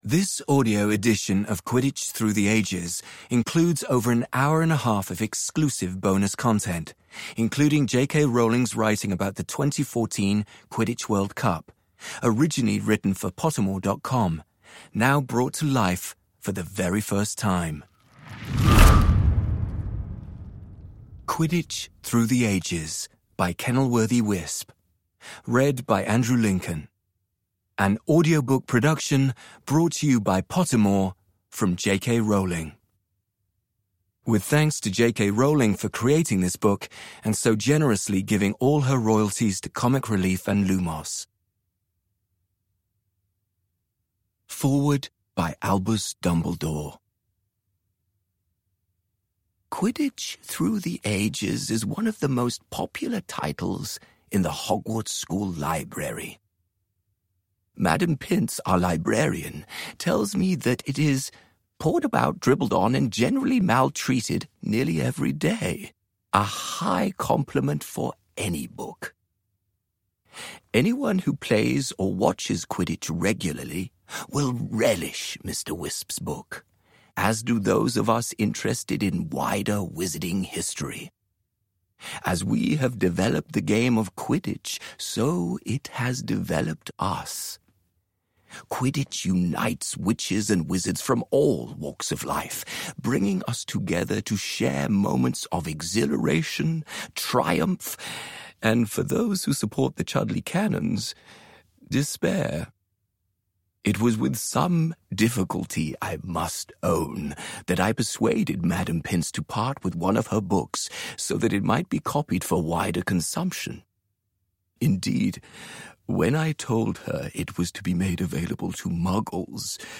Quidditch Through the Ages: A Harry Potter Hogwarts Library Book (ljudbok) av J.K. Rowling